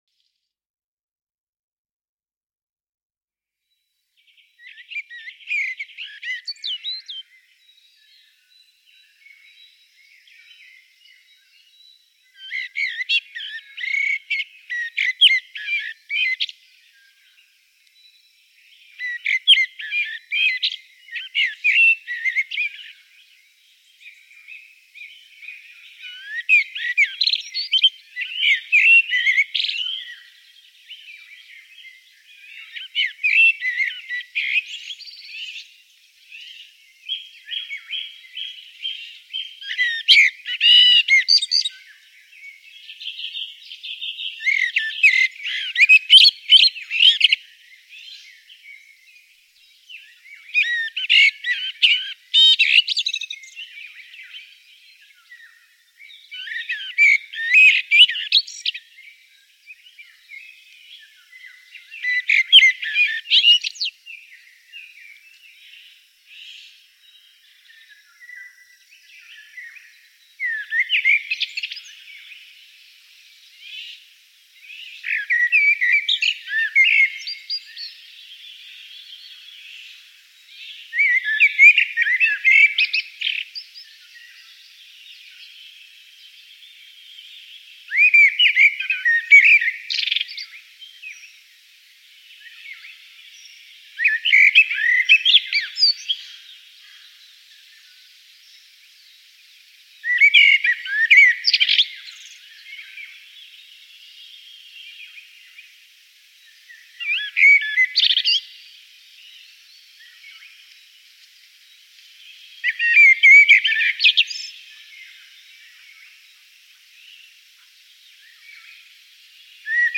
Amsel